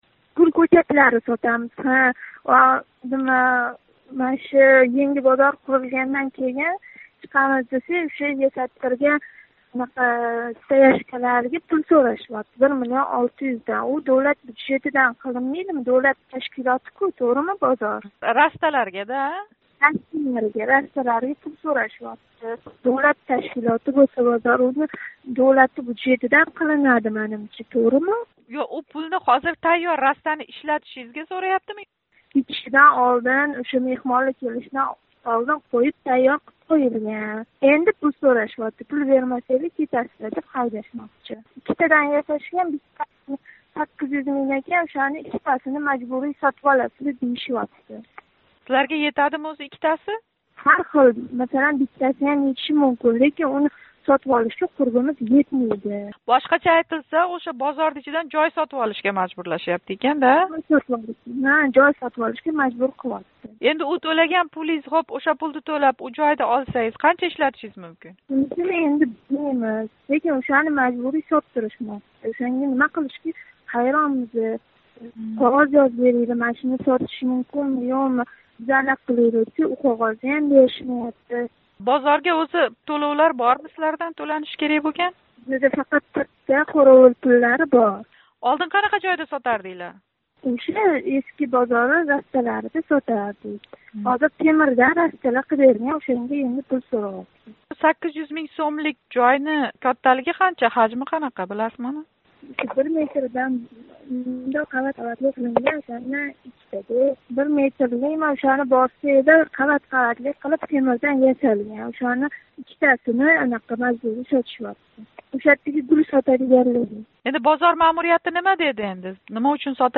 Қуйида бозор сотувчиси билан суҳбатни тинглашингиз мумкин: